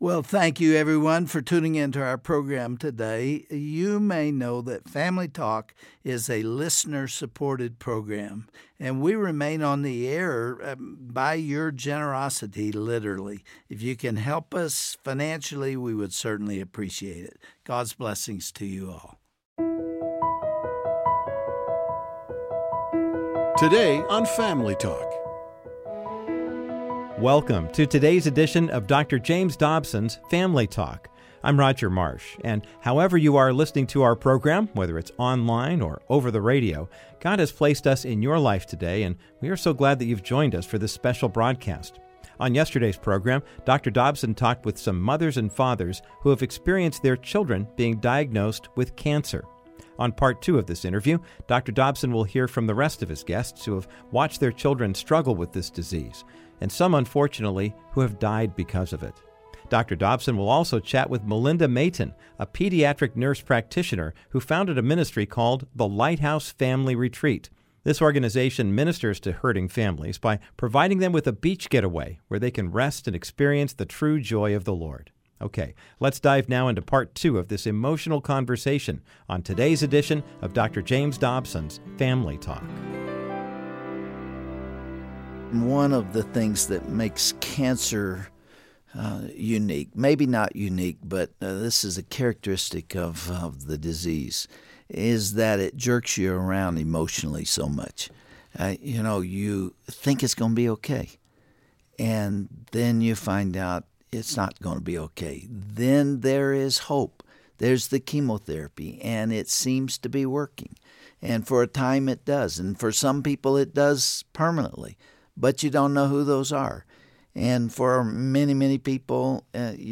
There is a tremendous emotional weight that parents carry when their children are diagnosed with cancer. Dr. Dobson will continue his conversation with three parents who have dealt with or are still enduring the effects of childhood cancer.